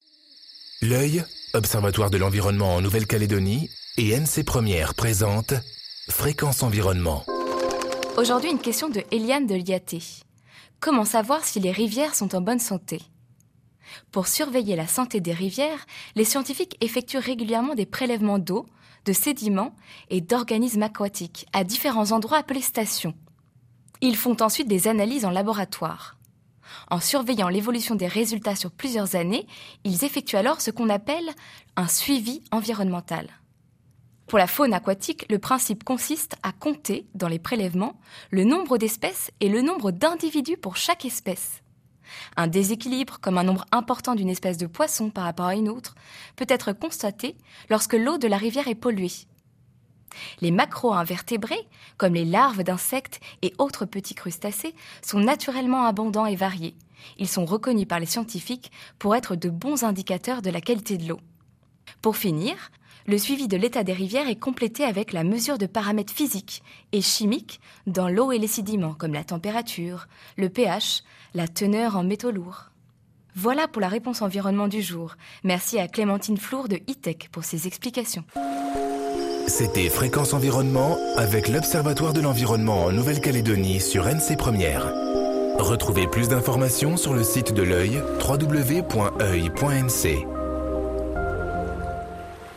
diffusée en décembre 2013 sur NC 1ère